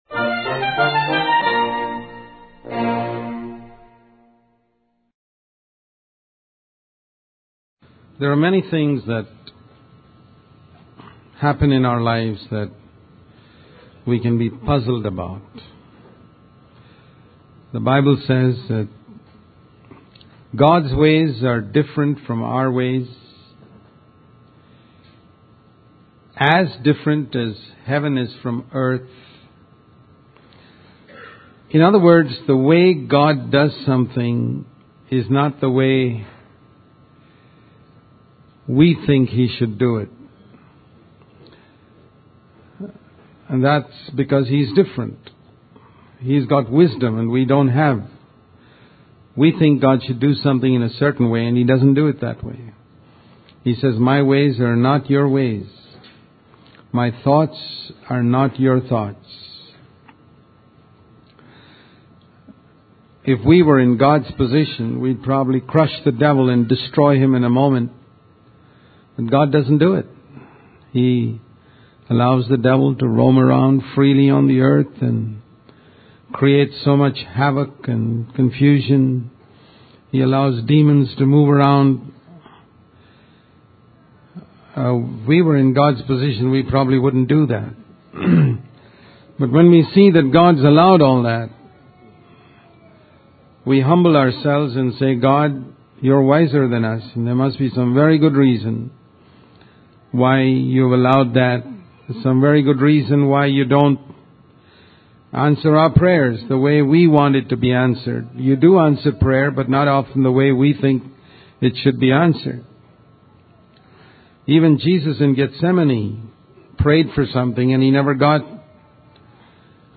In this sermon, the speaker discusses the feeling of hopelessness that can arise when we repeatedly fail in our attempts to live a holy life. He uses the example of the disciples who, after a night of unsuccessful fishing, were ready to give up.